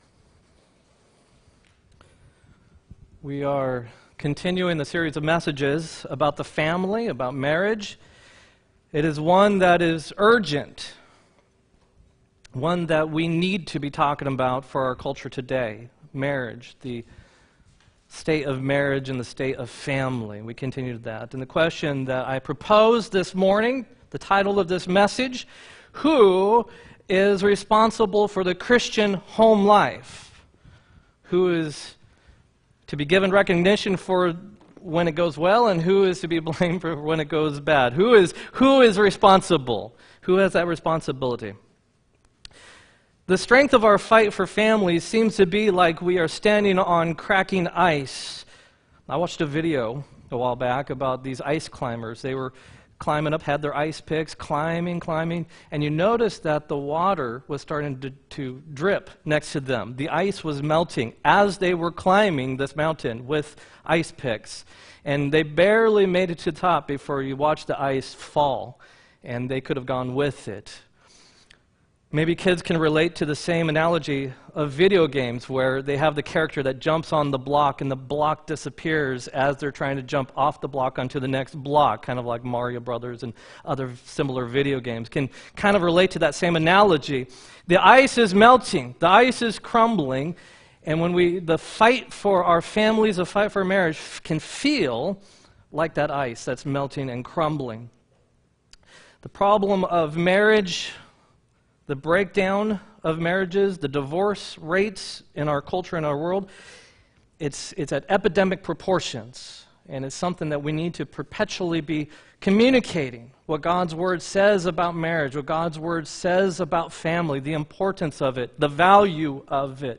6-9-18 sermon
6-9-18-sermon.m4a